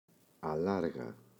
αλάργα [aꞋlarγa]